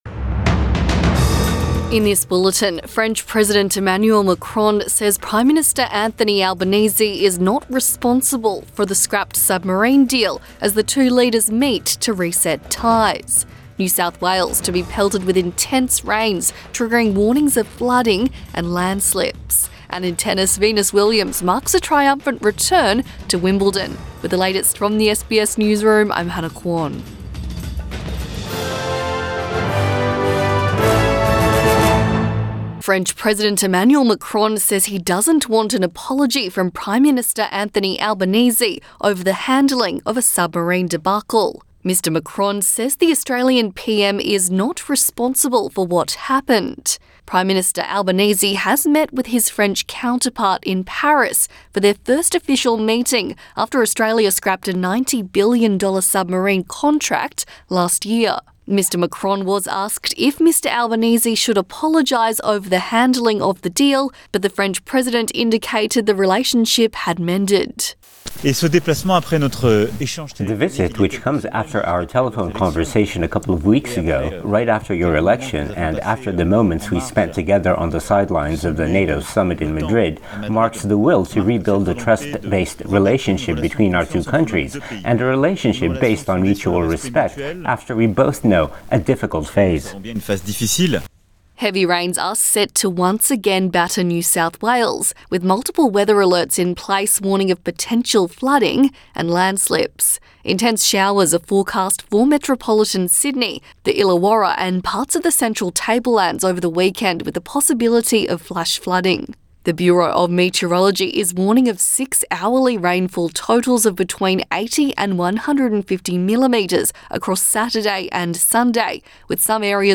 Midday bulletin 2 July 2022